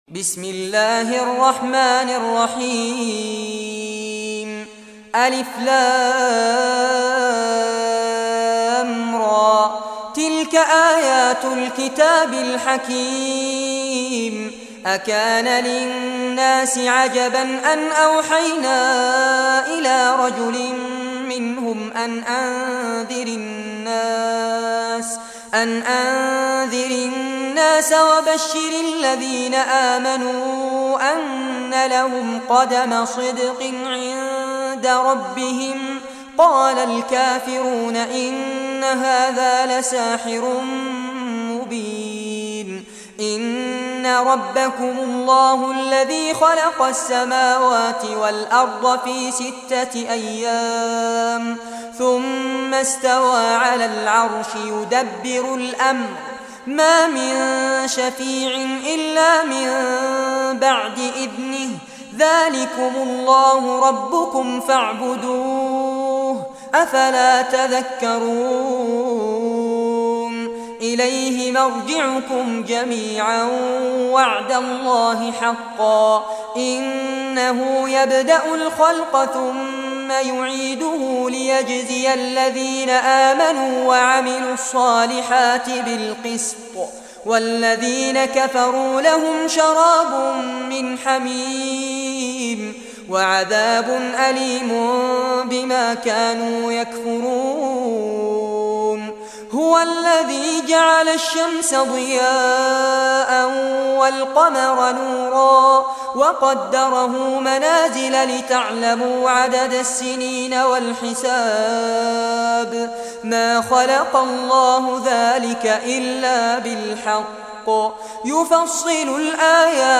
Surah Yunus Recitation by Sheikh Fares Abbad
Surah Yunus, listen or play online mp3 tilawat / recitation in Arabic in the beautiful voice of Sheikh Fares Abbad.
10-surah-yunus.mp3